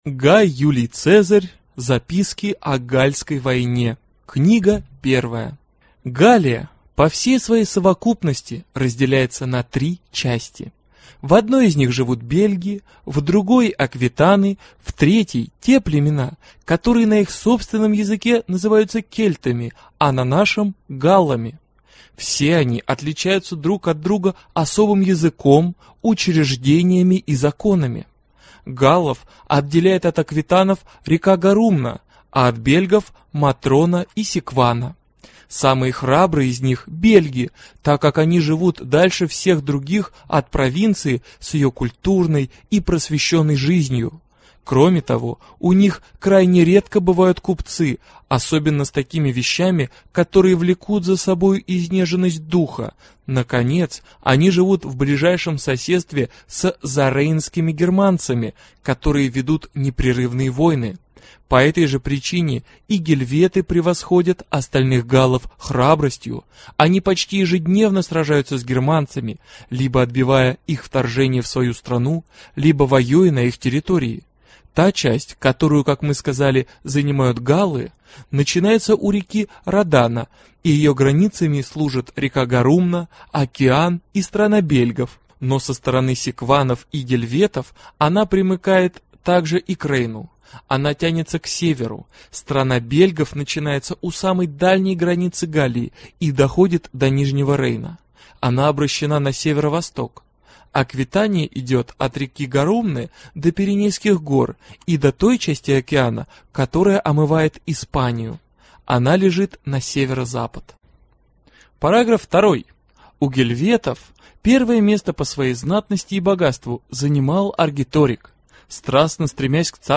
Аудиокнига Записки о Галльской войне | Библиотека аудиокниг